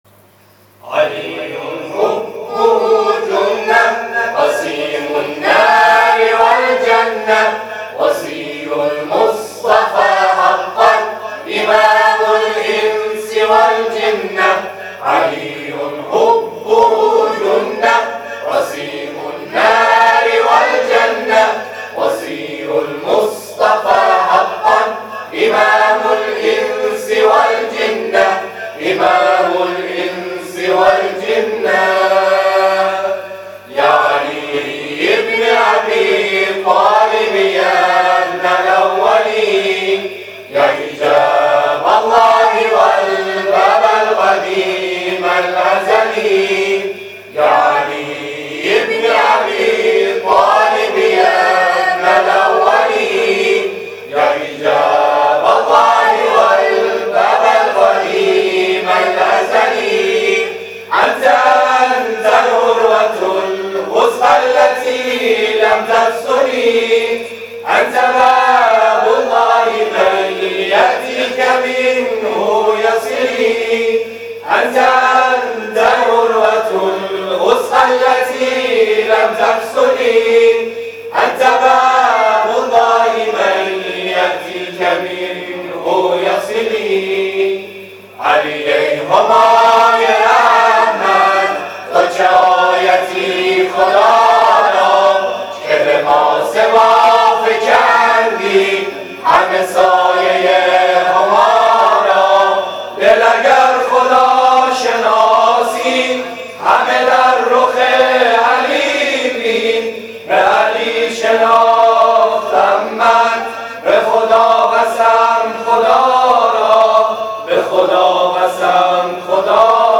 گروه جلسات و محافل ــ محفل انس با قرآن در جمعه آخر سال ۹۶ همزمان با نماز مغرب و عشاء در آستان مقدس امامزاده سید ابو رضا(ع) برگزار شد.
گروه تواشیح احسان